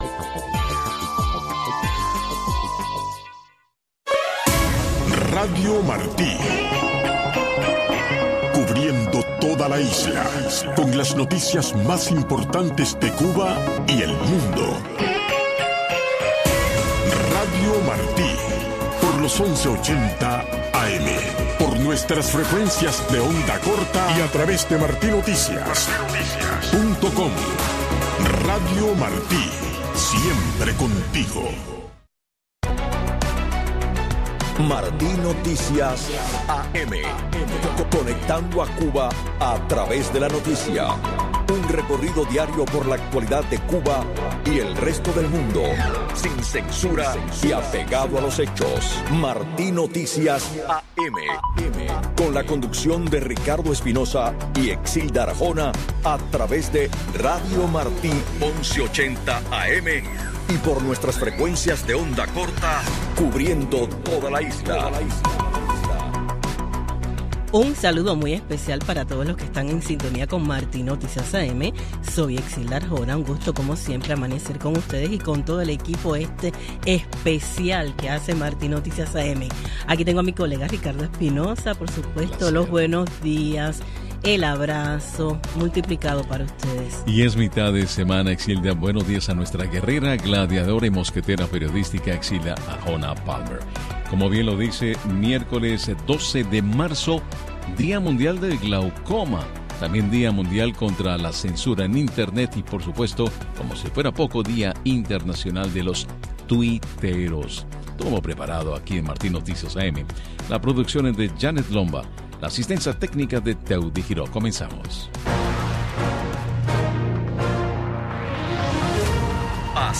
Revista informativa con los últimos acontecimientos ocurridos en Cuba y el mundo. Con entrevistas y temas de actualidad relacionados a la política, la economía y de interés general.